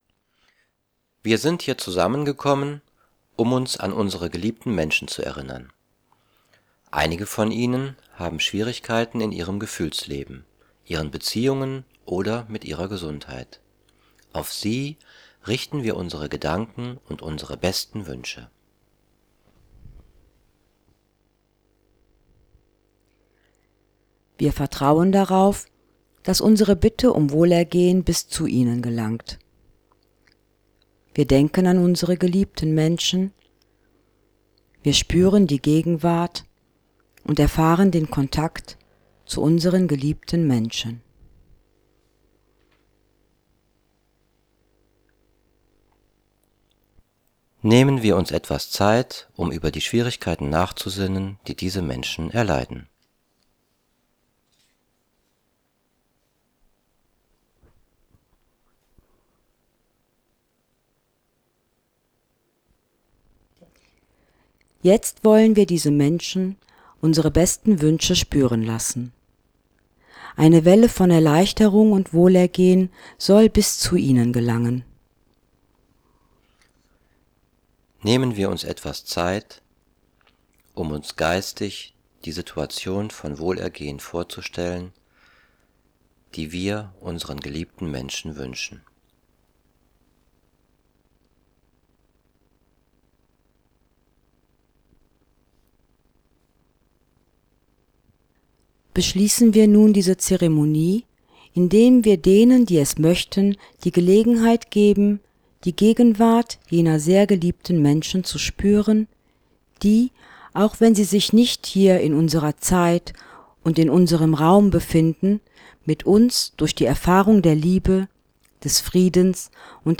Zeremonien: Wohlergehen
Zelebrant und Helfer stehen.